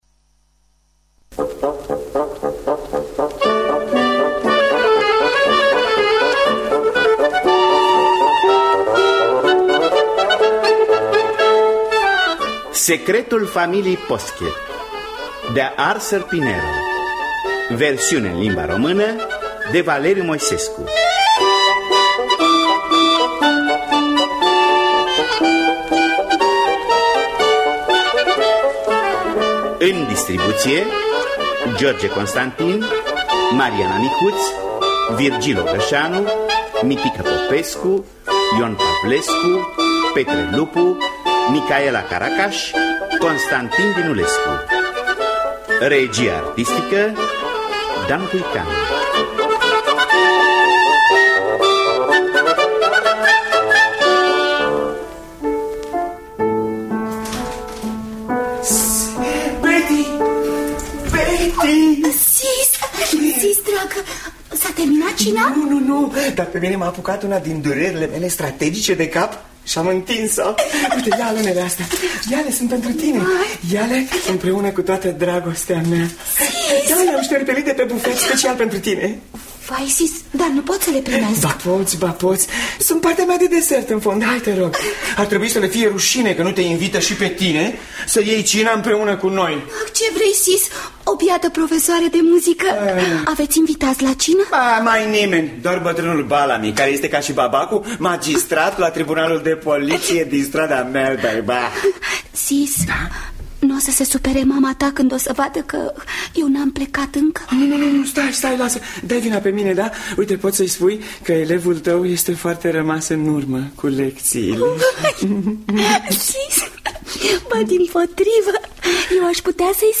Secretul familiei Posket de Arthur Wing Pinero – Teatru Radiofonic Online